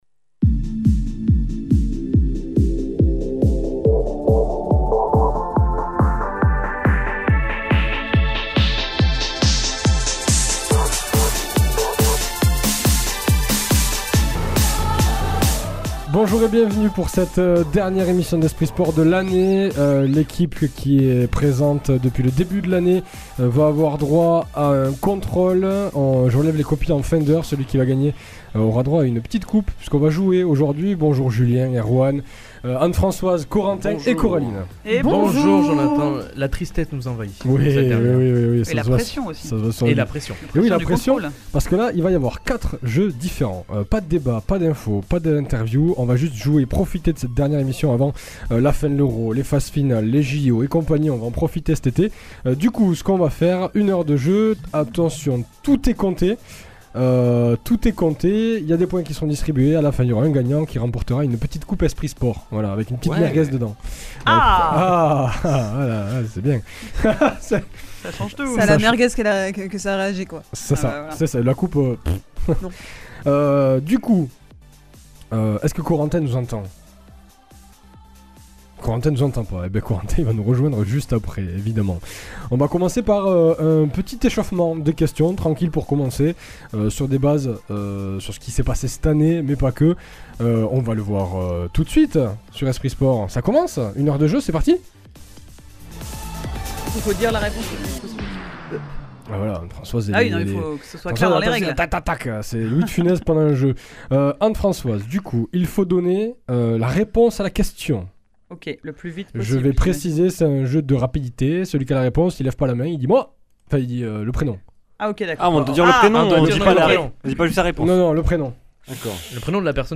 L’équipe est au complet juste avant les vacances !